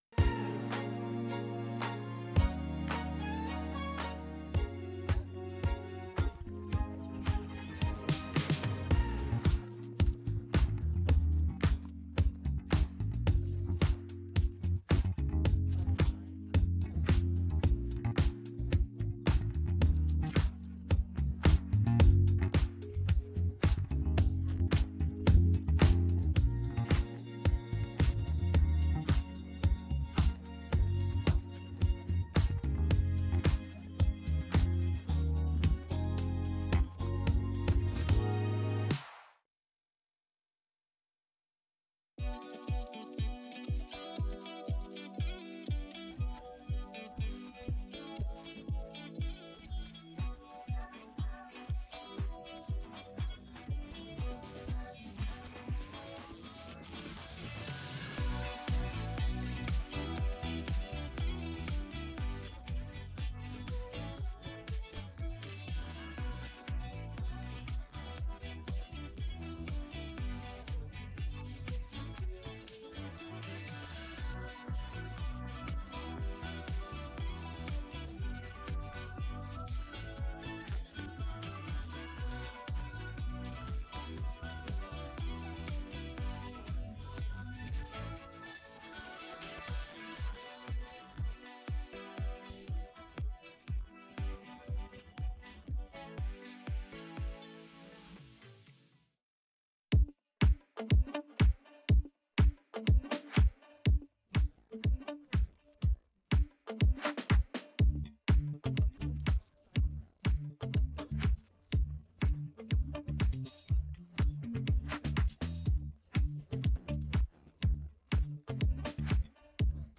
Background music
Disco!
I used AI to remove the "vocals"